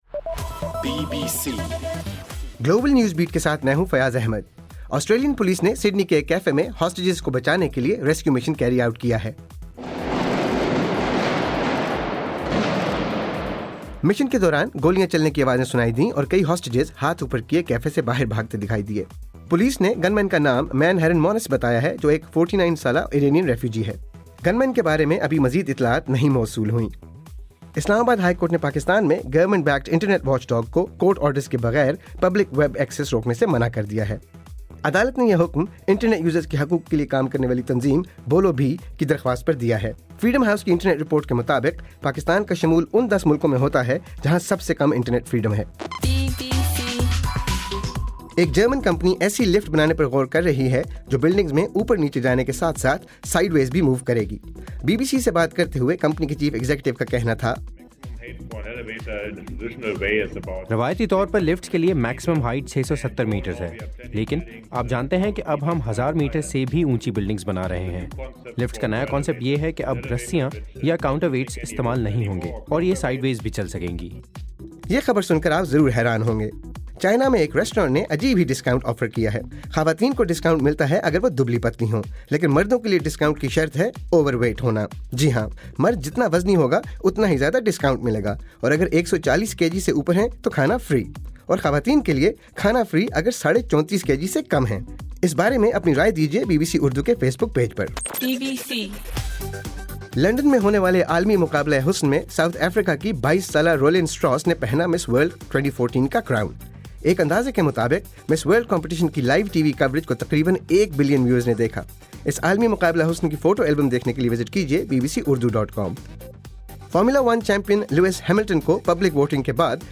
دسمبر 15: رات 10 بجے کا گلوبل نیوز بیٹ بُلیٹن